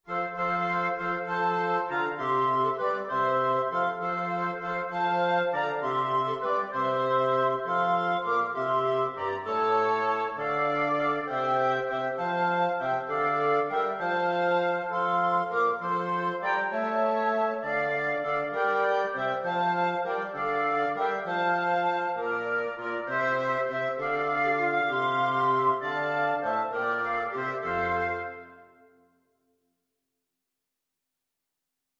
FluteOboeClarinetBassoon
3/4 (View more 3/4 Music)
On in a bar . = c. 66
Wind Quartet  (View more Easy Wind Quartet Music)